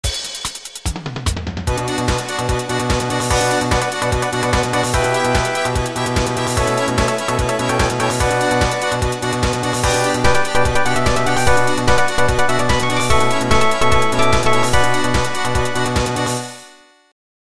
学習ゲームのＢＧＭ用に作曲していただいた、完全オリジナル曲です。